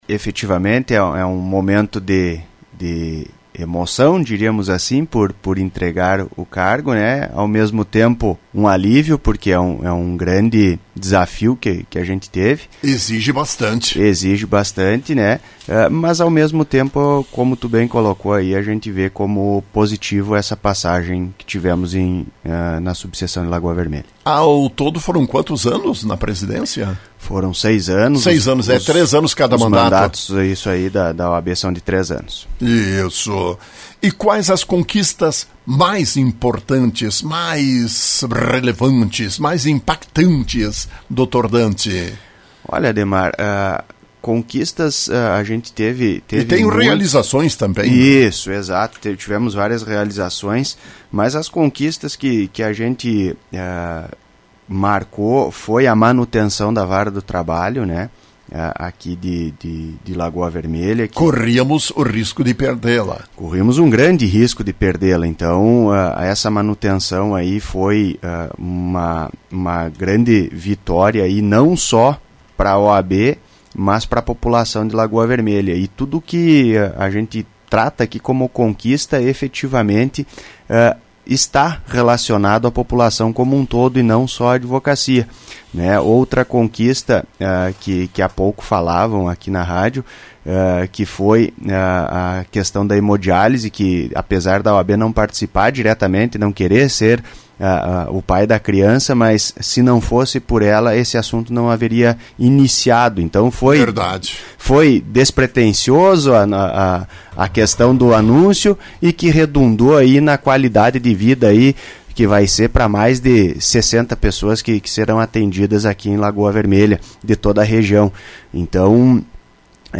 Na manhã desta quinta-feira, entrevistado pela Rádio Lagoa FM, elencou suas principais realizações e conquistas.